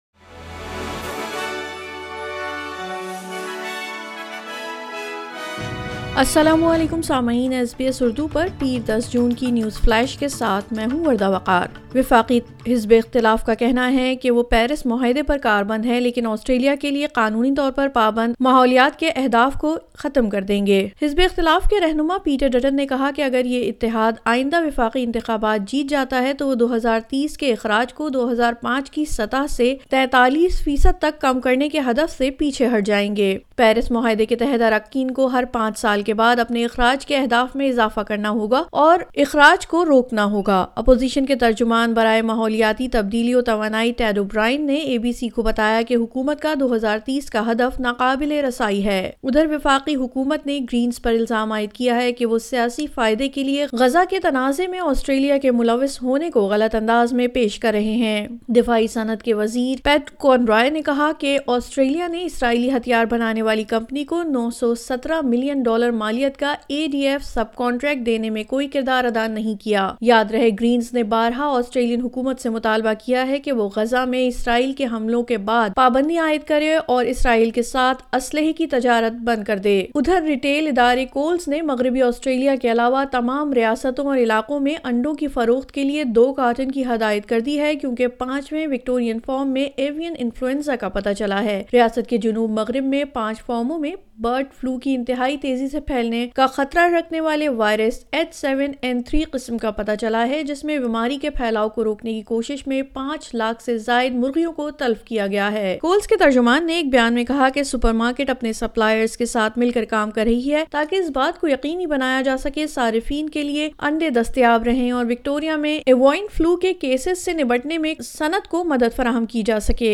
نیوز فلیش:10 جون 2024: وکٹوریہ میں ایک اور فارم میں برڈ فلو، کولز کی جانب سے انڈوں کی فروخت پر حد عائد